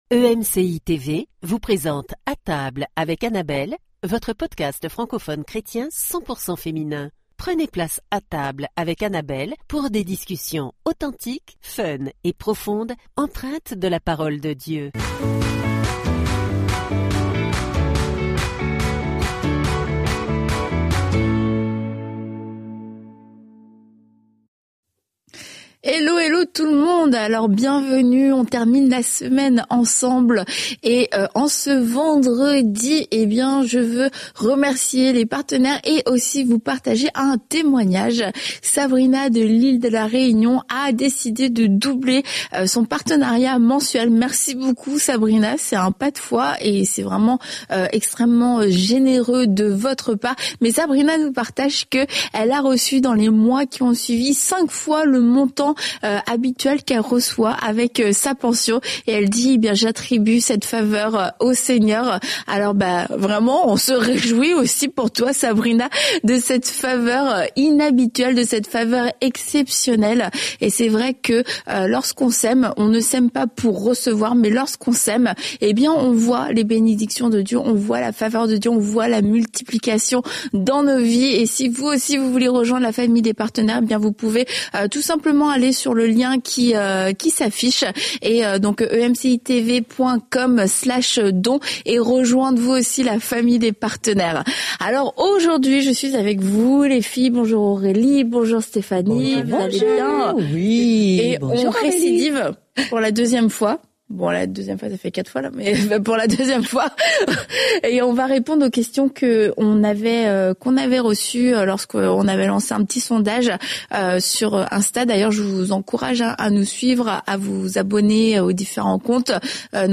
Autour de la table, les chroniqueuses répondent à vos questions sur des sujets essentiels : l'idolâtrie des hommes de Dieu, l'isolement, le burn-out parental et ses signes (troubles de l'humeur, fatigue, désintérêt...) ainsi que la manière de surmonter les pensées négatives.